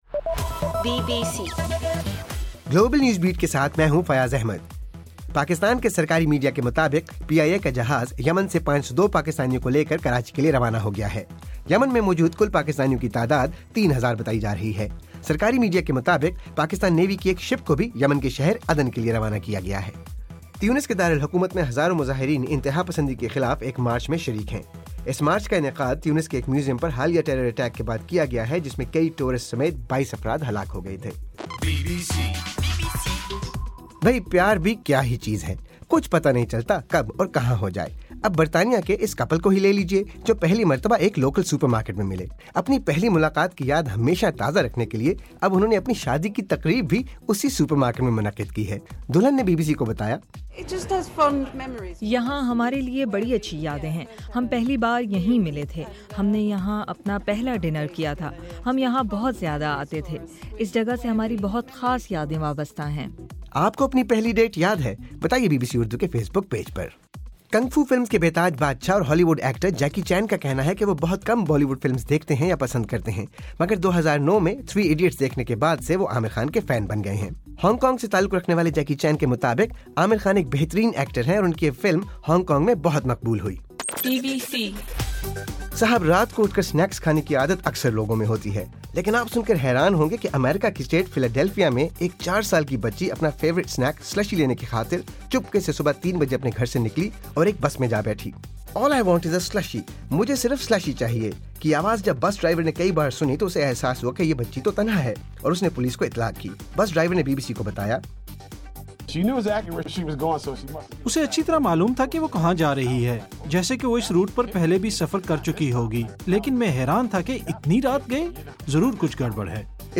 مارچ 29: رات 12 بجے کا گلوبل نیوز بیٹ بُلیٹن